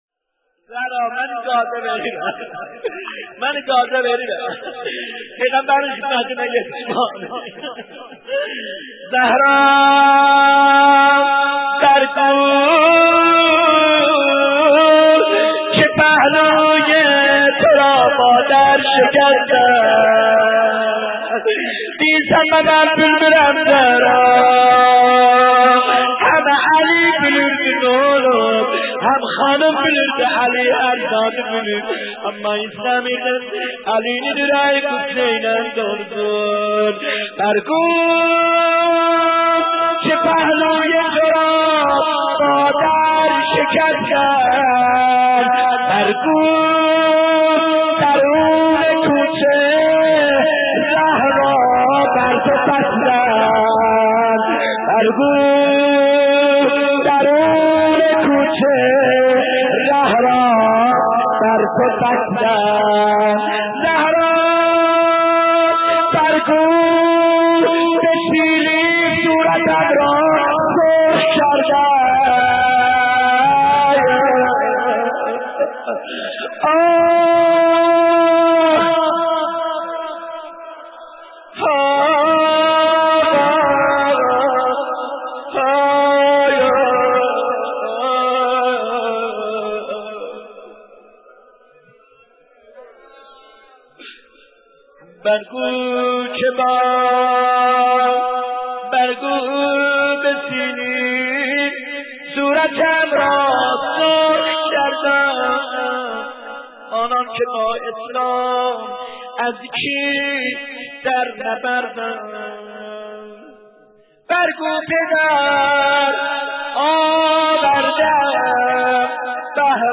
دانلود مداحی آچ گوزون منی دیندیر - دانلود ریمیکس و آهنگ جدید